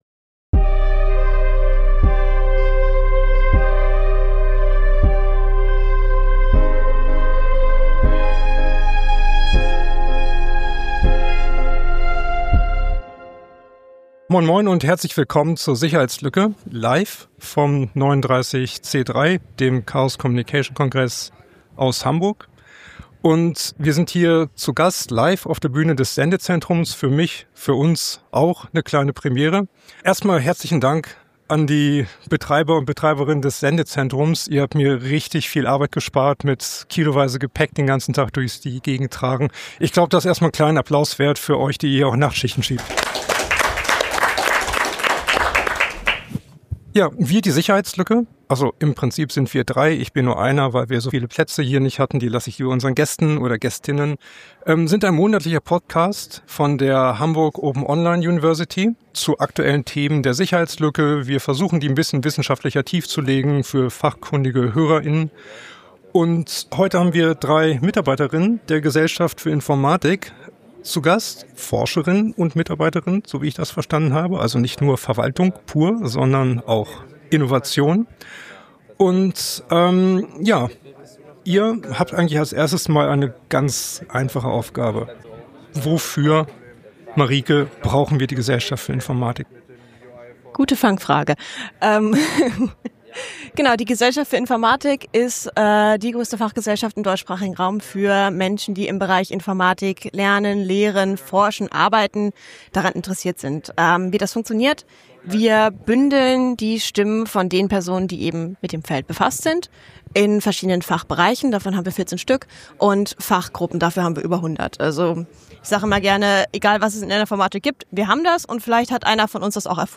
Wir senden in diesem Jahr jeden Tag live vom 39C3, dem 39. Chaos Communication Congress in Hamburg. In dieser Folge sprechen wir auf der Bühne des Sendezentrums über die Vorträge von Tag 1, die wir empfehlen möchten, und darüber, weshalb wir sie interessant und relevant finden.